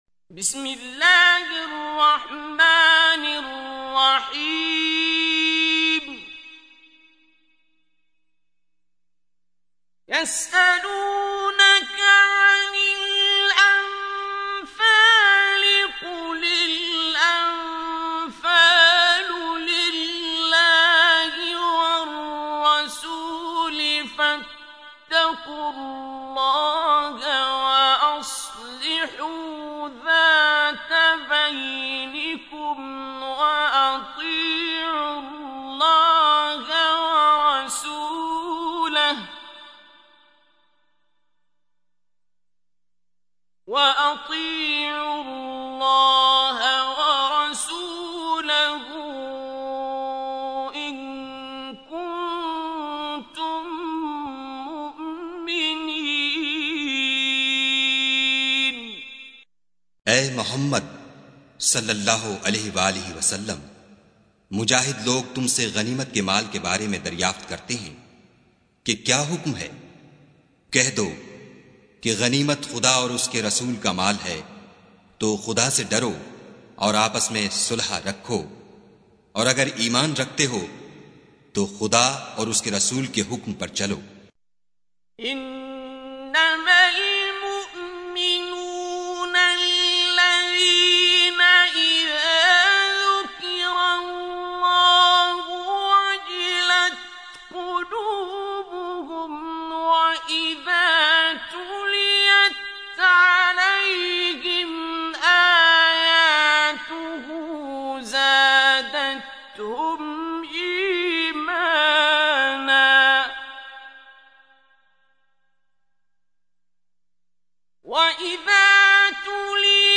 Tilawat by Qari Abdul Basit As Samad.